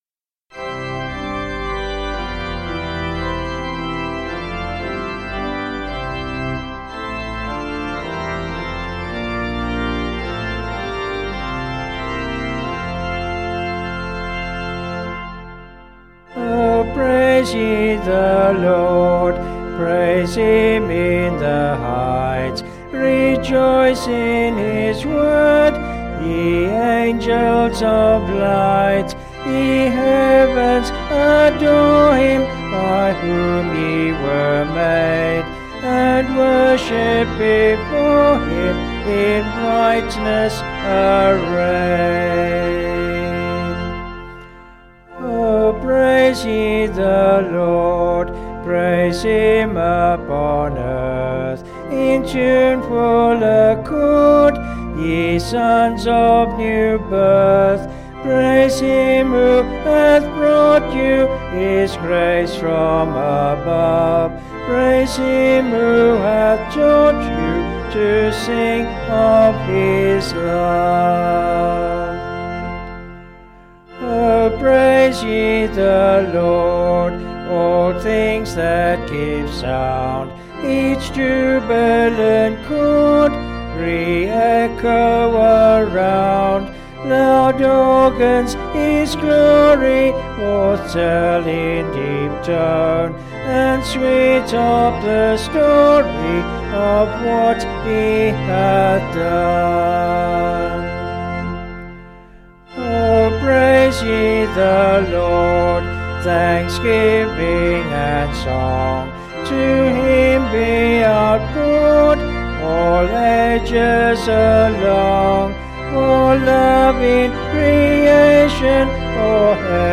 Vocals and Organ   263.1kb Sung Lyrics